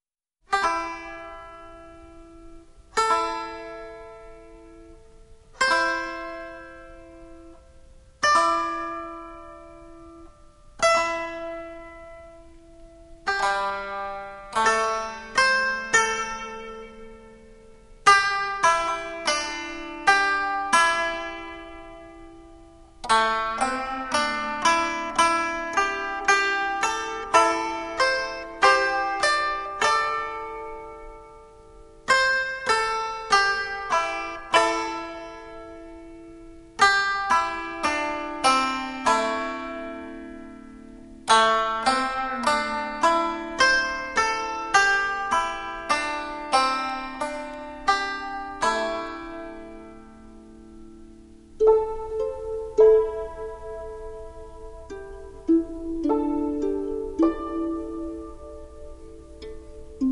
CD 1 Original Motion Picture Soundtrack